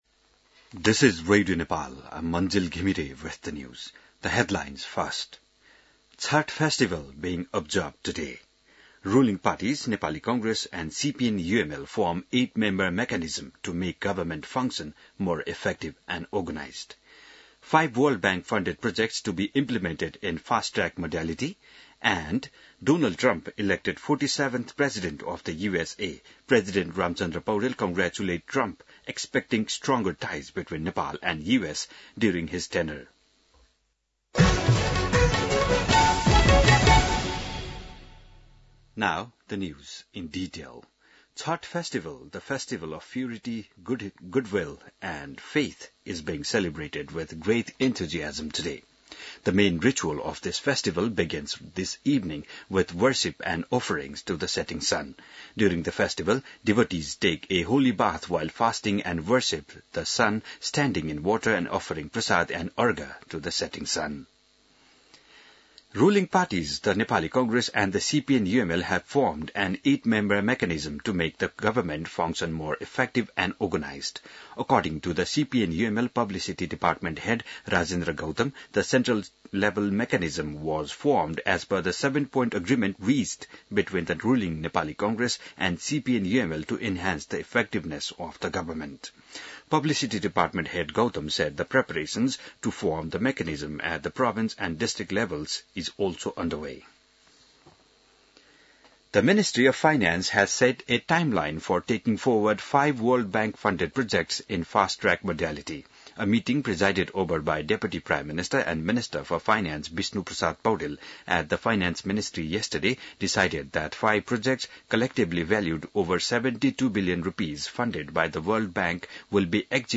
बिहान ८ बजेको अङ्ग्रेजी समाचार : २३ कार्तिक , २०८१